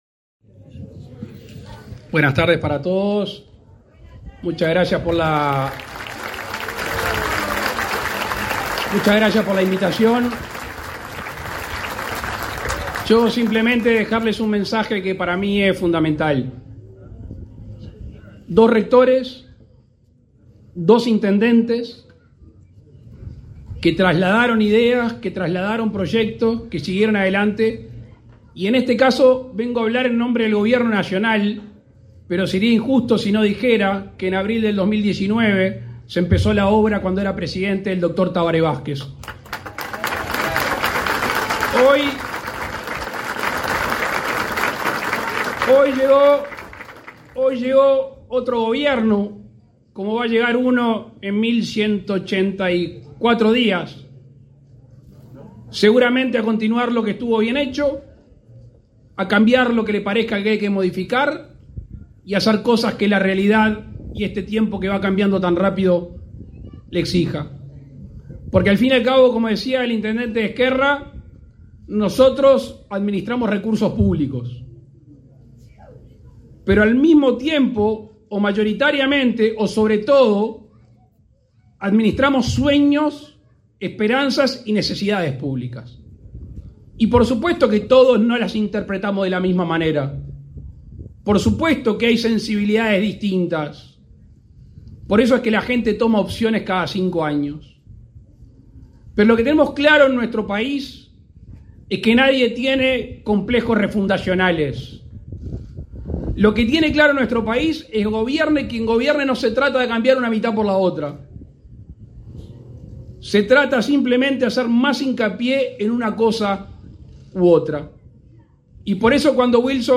Palabras del presidente de la República, Luis Lacalle Pou
El presidente Luis Lacalle Pou participó en la inauguración de la Residencia Universitaria en Tacuarembó, este 29 de noviembre.